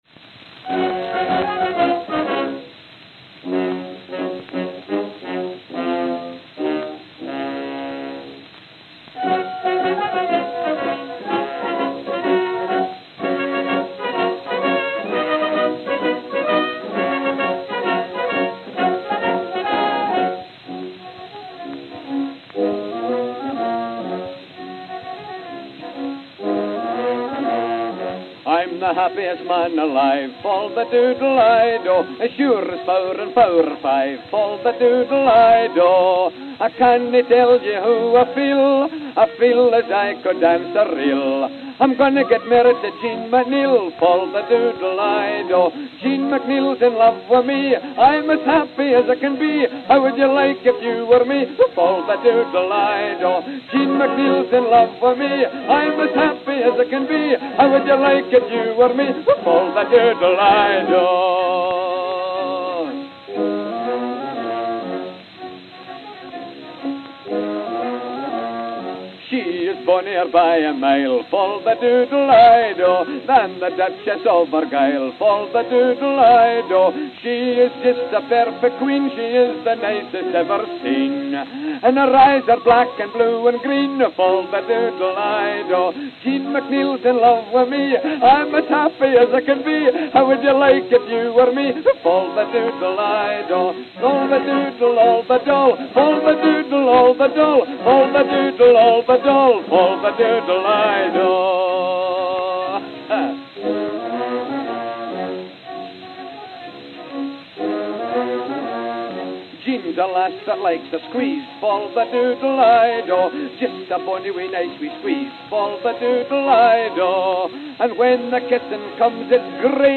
August 16, 1906 (London, England) (1/11)